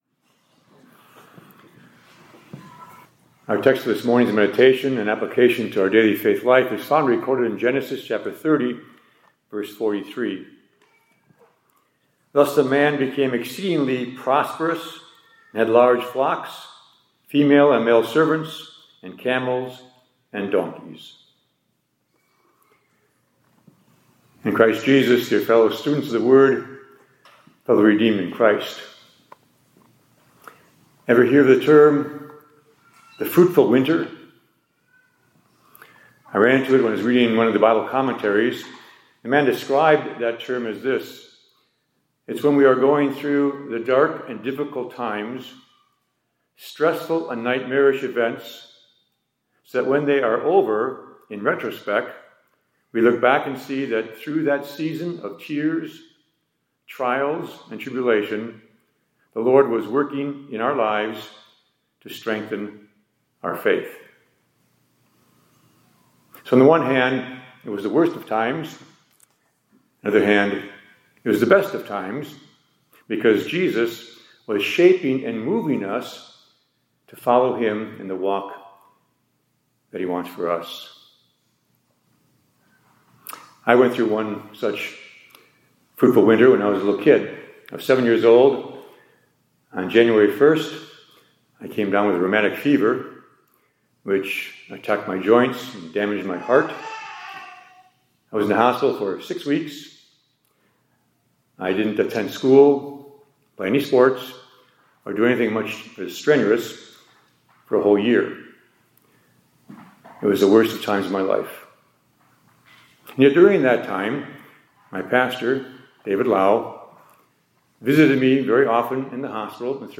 2025-10-13 ILC Chapel — God Blesses You with the…